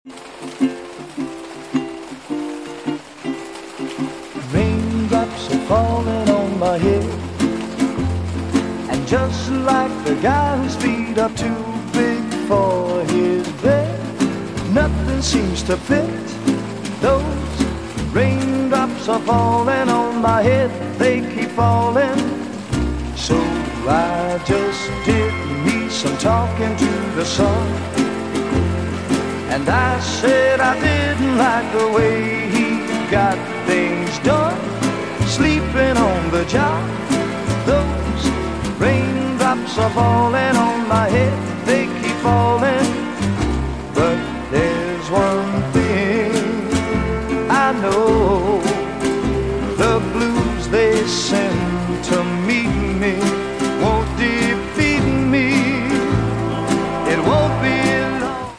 Relaxing Rain to Fall Asleep sound effects free download
Relaxing Rain to Fall Asleep Fast in 5 Minutes - 4 Hours of Ambient ASMR Sounds